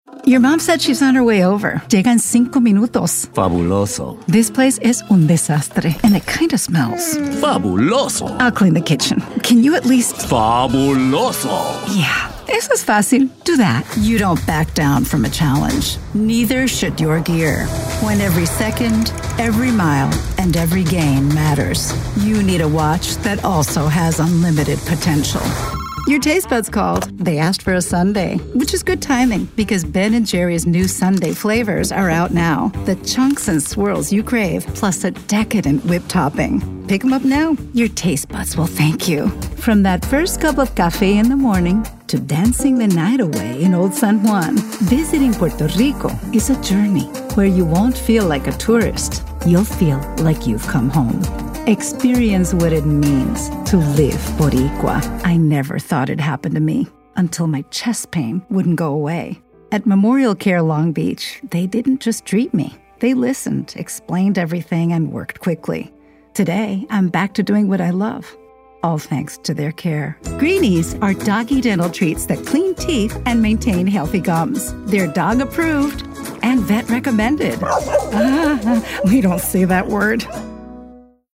Commercial Reel - English accented English, announcer, anti-announcer, authoritative, compelling, confident, english-showcase, genuine, professional, promo, real, sincere, upbeat